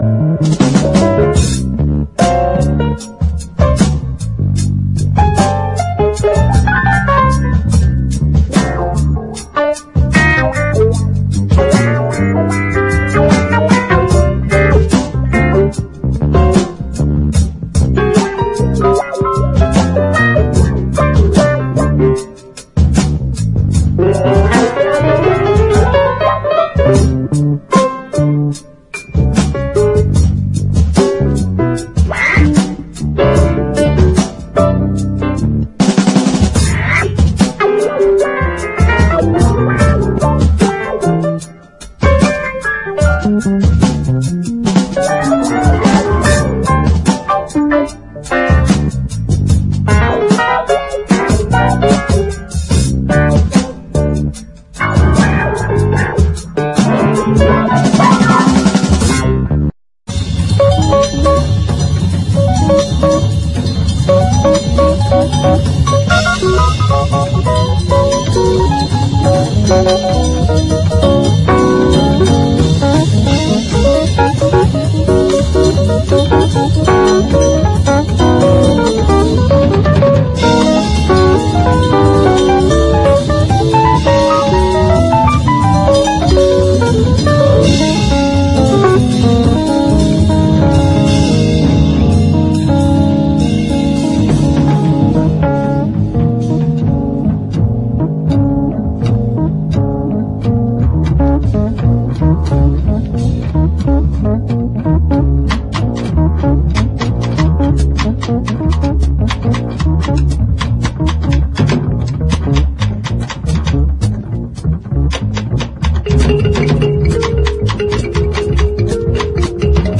JAZZ / MAIN STREAM / PIANO / EASY LISTENING
しっとり繊細なタッチの美しい
緩急ある演奏が魅力的です。 MONO/深溝/US ORIGINAL盤！